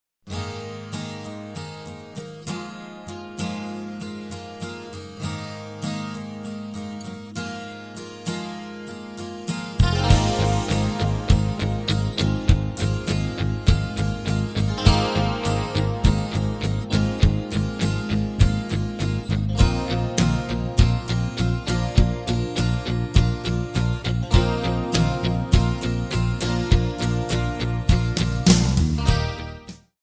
D
MPEG 1 Layer 3 (Stereo)
Backing track Karaoke
Pop, Rock, 1980s